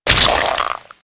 splat.mp3